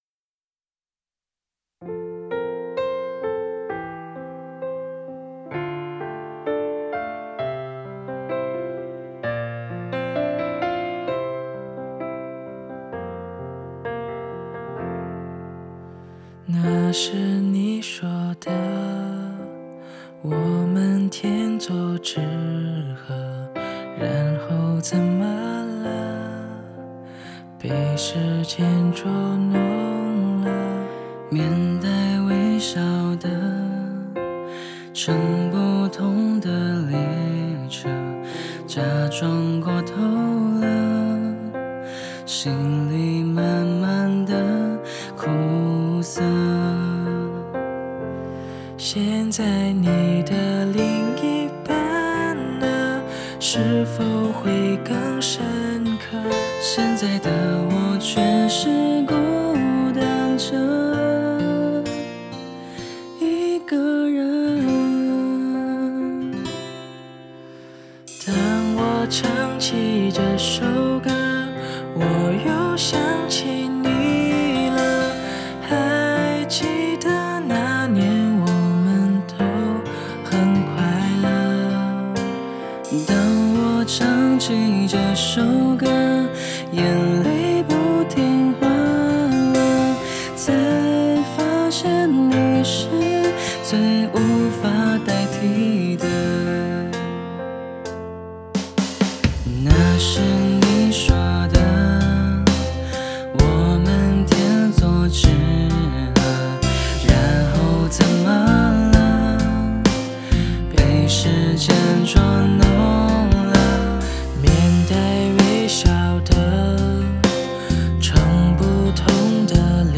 在线试听为压缩音质节选